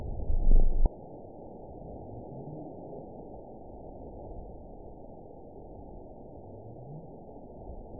event 912526 date 03/28/22 time 21:16:54 GMT (3 years, 1 month ago) score 9.68 location TSS-AB04 detected by nrw target species NRW annotations +NRW Spectrogram: Frequency (kHz) vs. Time (s) audio not available .wav